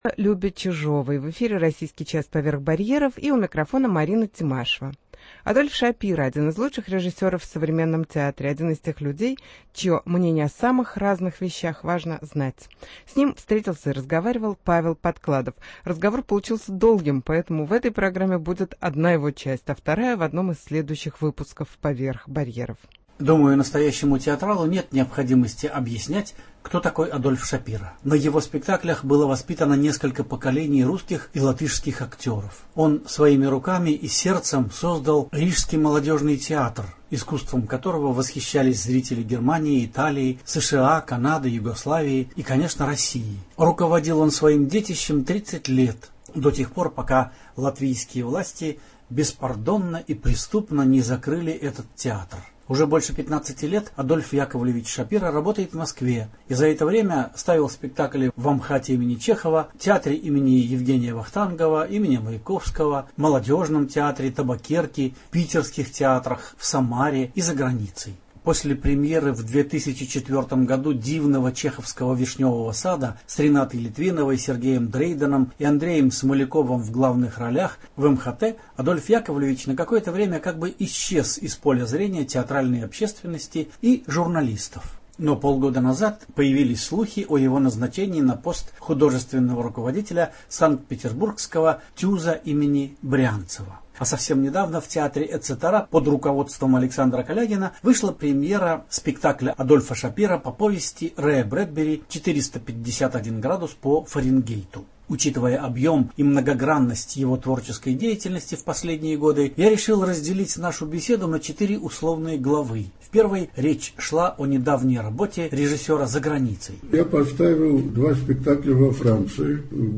Интервью с режиссером Адольфом Шапиро